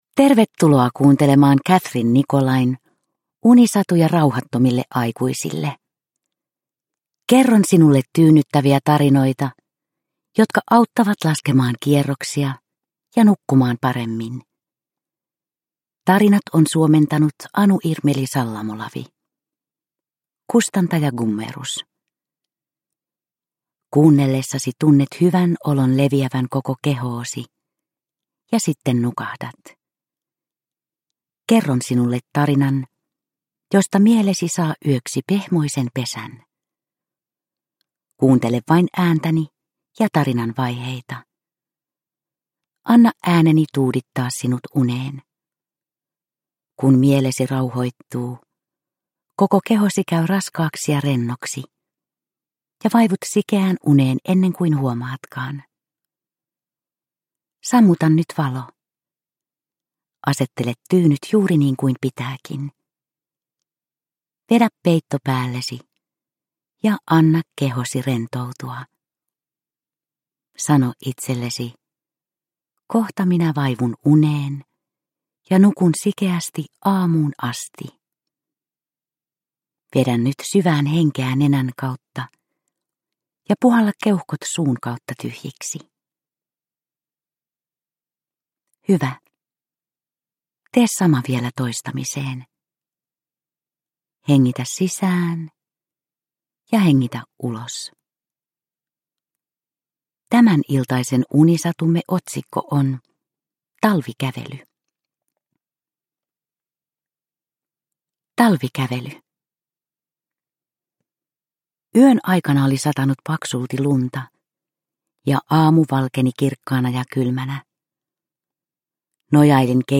Unisatuja rauhattomille aikuisille 14 - Talvikävely – Ljudbok – Laddas ner